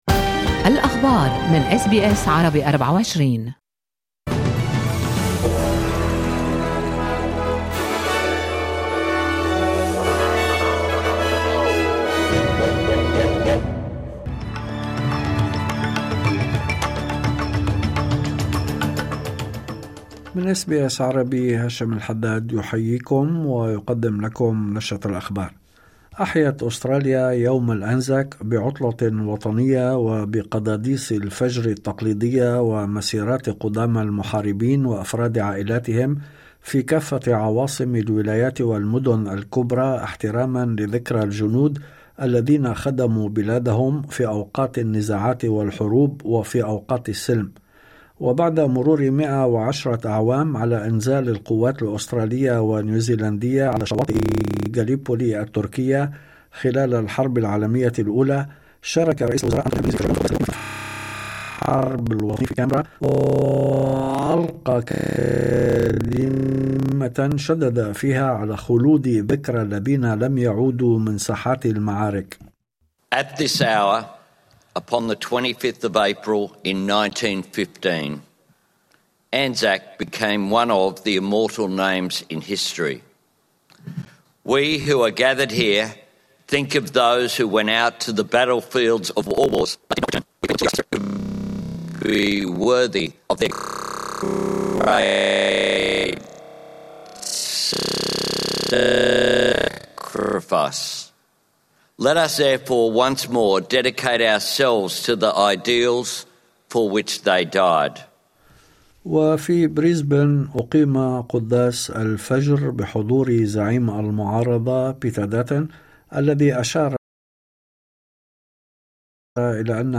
نشرة أخبار الظهيرة 25/04/2025